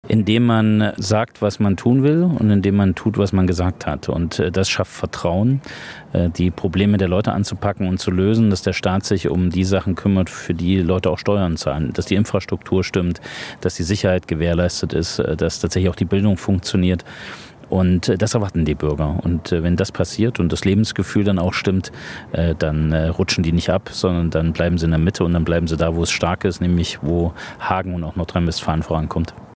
Er war gestern auf Einladung der Hagener CDU beim Bürgerplenum "Wir verteidigen die Demokratie" zu Gast.